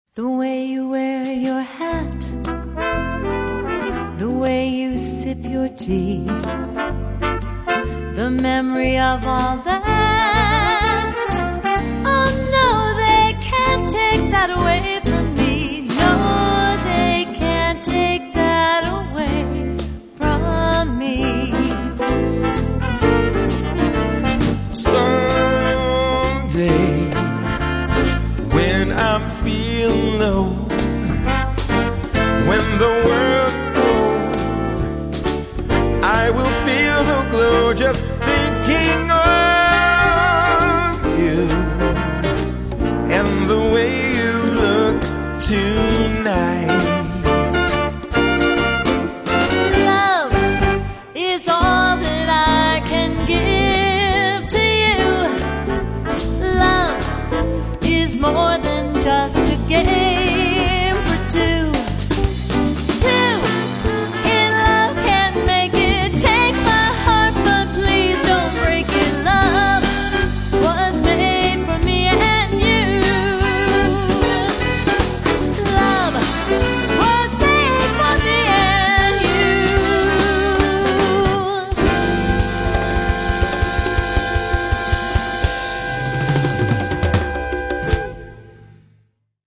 MA wedding bands